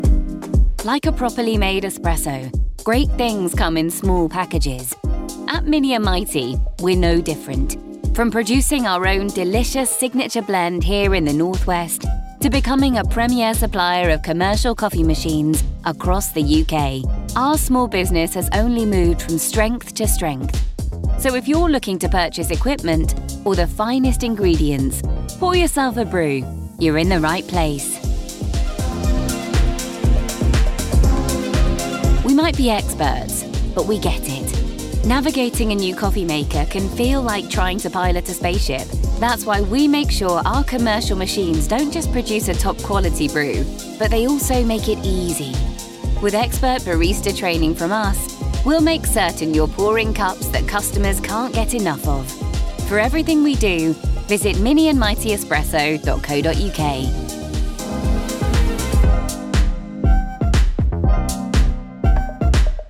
Natural, Playful, Versatile, Friendly, Warm
Telephony